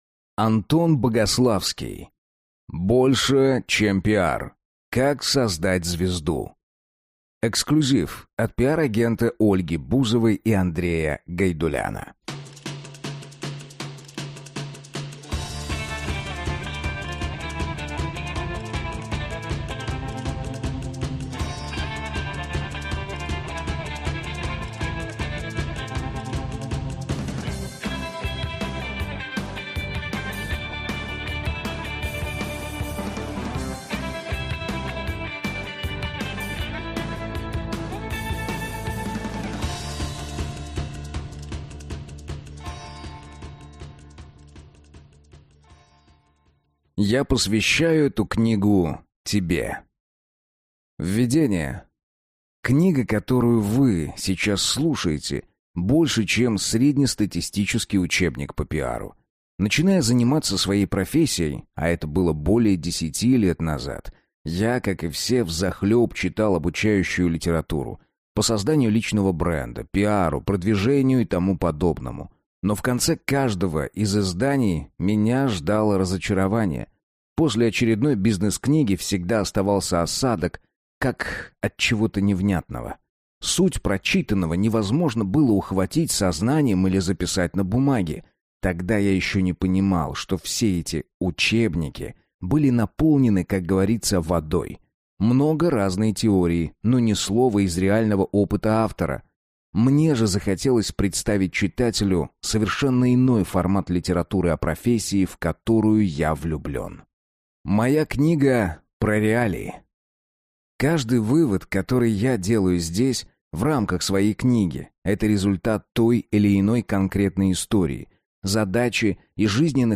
Аудиокнига Больше чем пиар. Как создать звезду | Библиотека аудиокниг